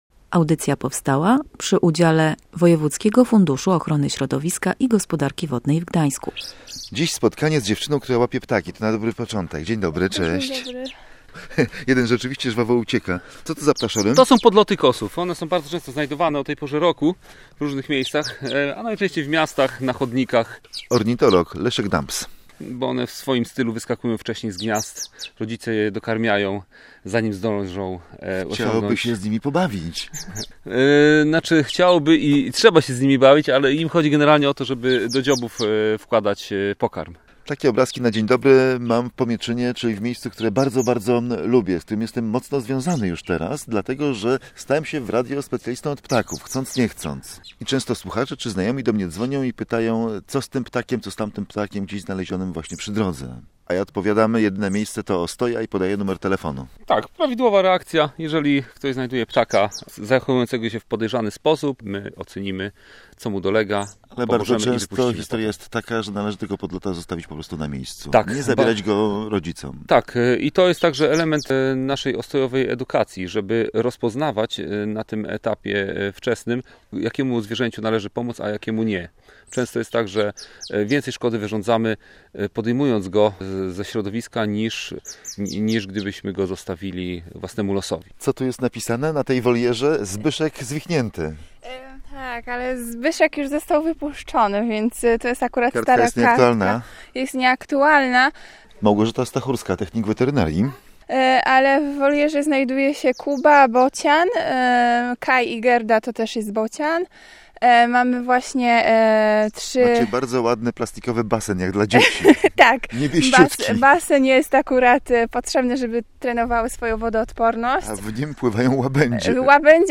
Posłuchaj audycji o zwierzętach leczonych w Ostoi/audio/dok1/eko-pomoc.mp3